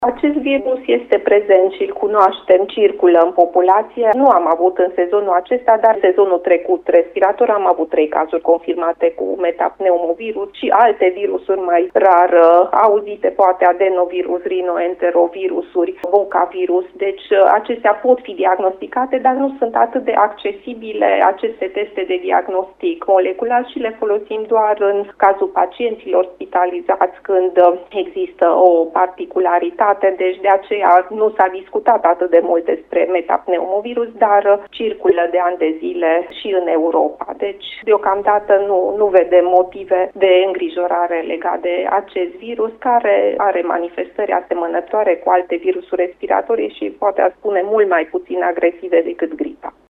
Invitată astăzi la postul nostru de radio